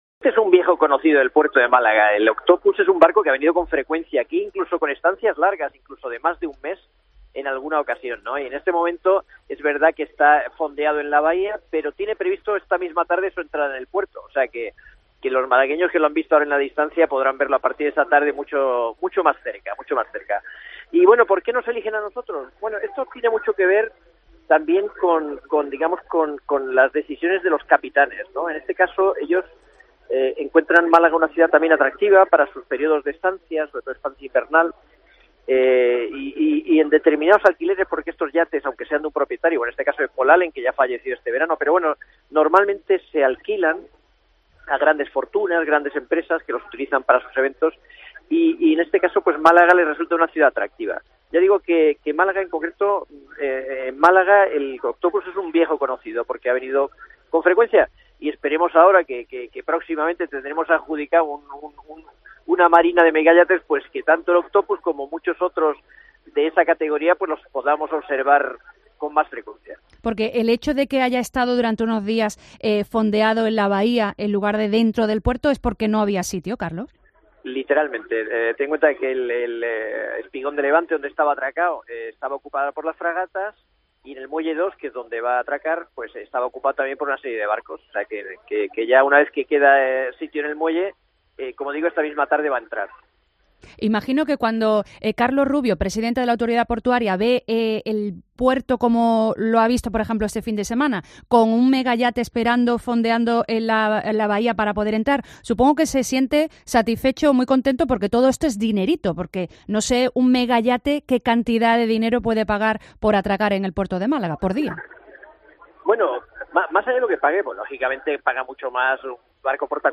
El presidente de la Autoridad Portuaria, Carlos Rubio en COPE Más Málaga.